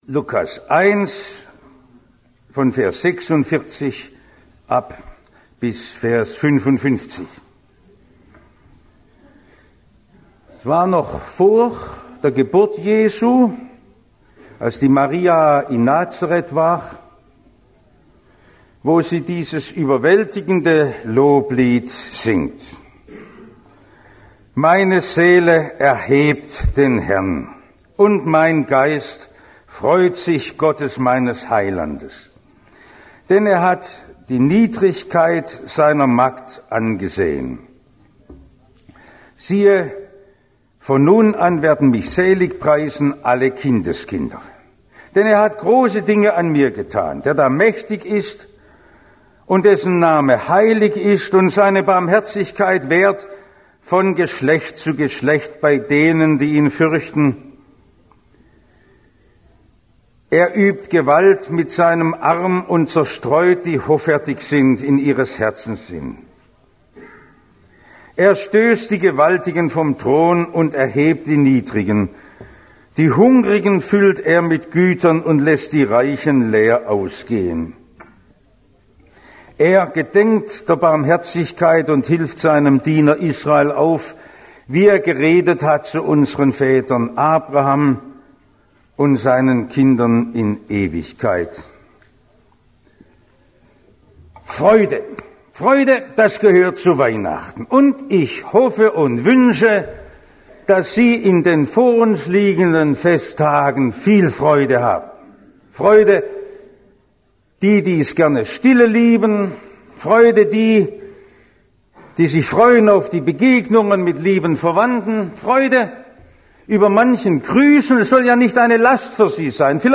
Predigt Audio Lk 1,46-55 Freuen, auch wenn man ganz unten ist